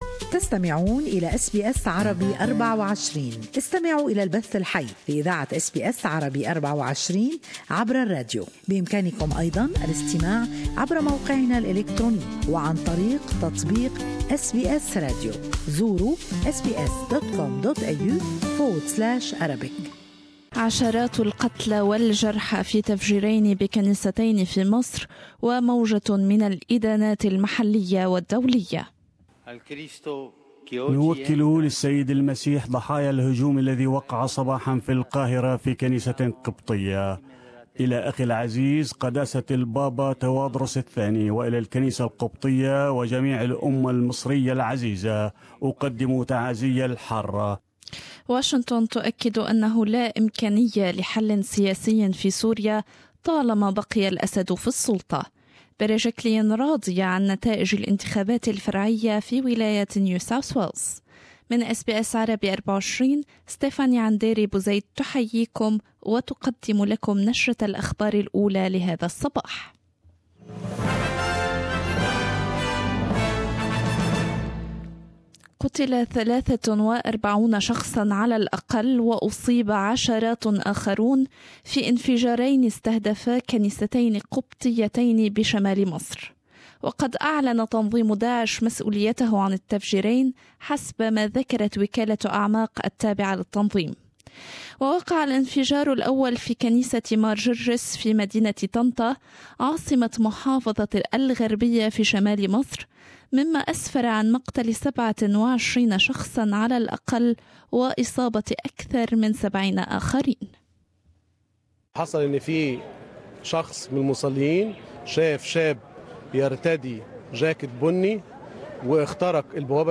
Latest Australian and world news in Morning news bulletin.